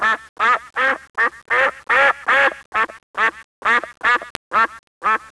rat_sound.wav